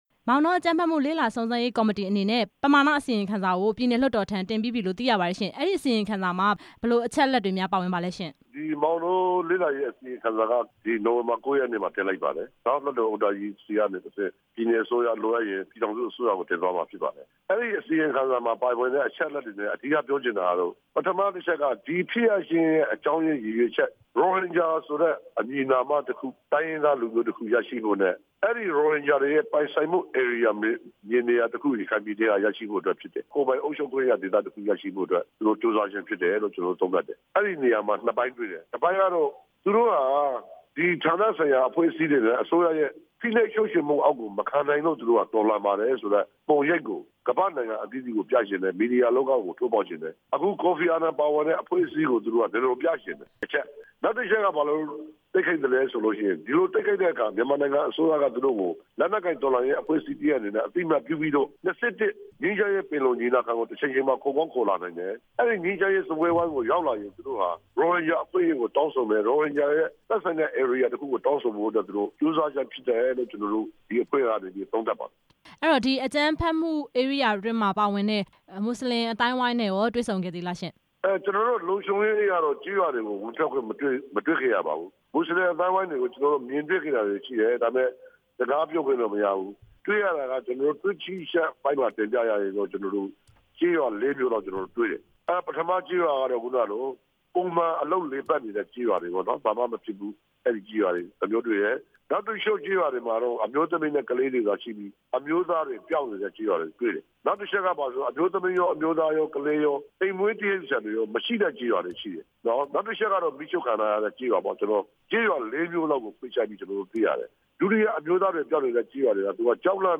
မောင်တောအကြမ်းဖက်ခံရမှုအကြောင်း မေးမြန်းချက်